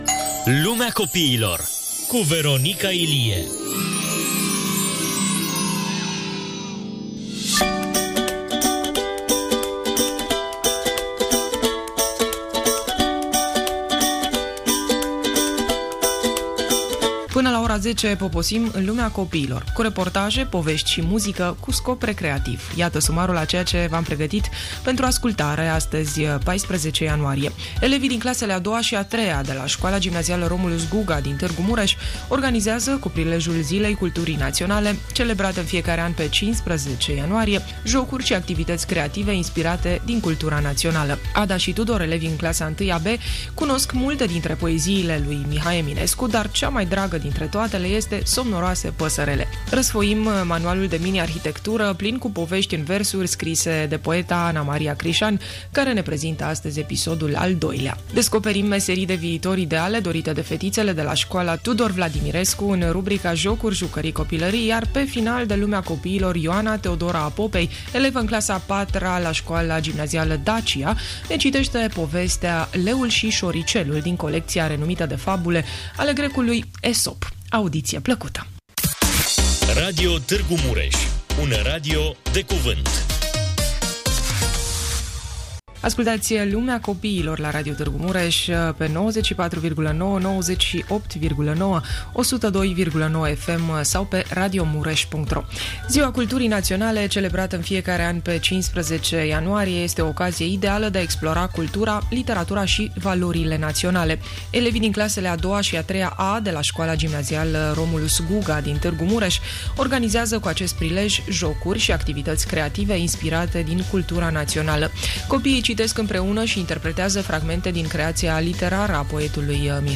Dimineața de duminică începe cu o incursiune în „Lumea copiilor”, cu reportaje, povești și muzică. Evadăm în universal celor mici, plin de zâmbete, curiozitate și aventuri inepuizabile și povestim despre ziua culturii naționale, prilej pentru jocuri și activități creative.